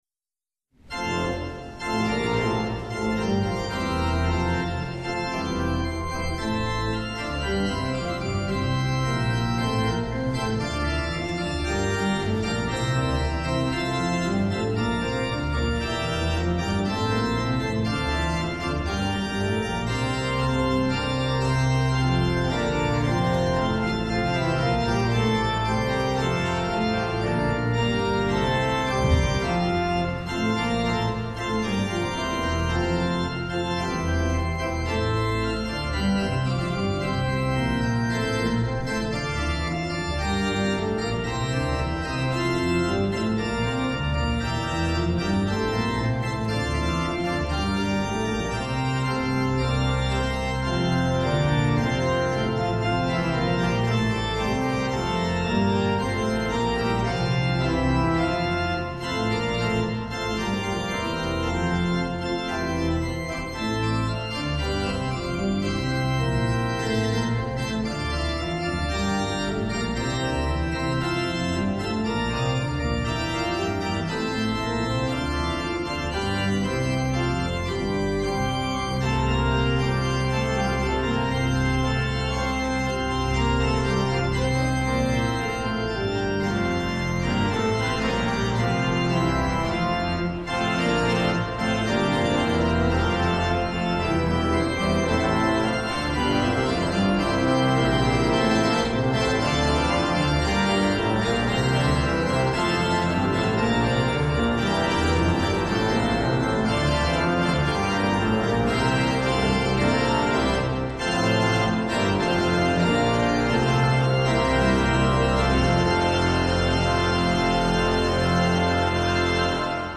Hear the Bible Study from St. Paul's Lutheran Church in Des Peres, MO, from April 12, 2026.
St. Paul’s Des Peres Bible Study — Acts 5:1-26